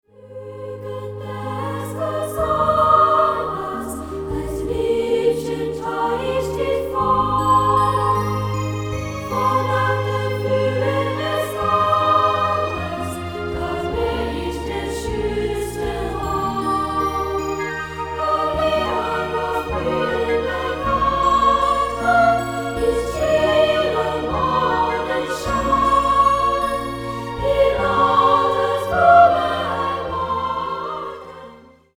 ☆收录从小唱到大的世界民谣，让您重温童年的美好记忆。